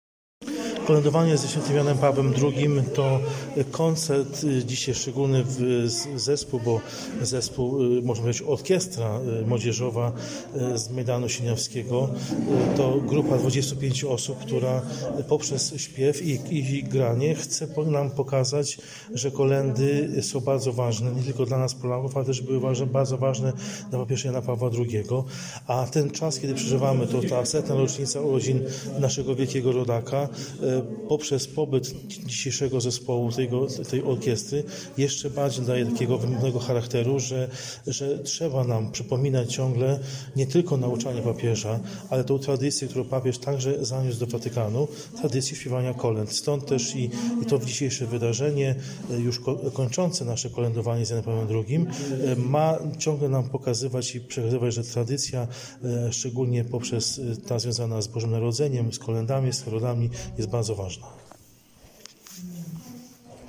Trzeba nam przypominać ciągle nie tylko nauczanie papieża, ale tę tradycję, którą papież także zaniósł do Watykanu, tradycję śpiewania kolęd – mówił podczas koncertu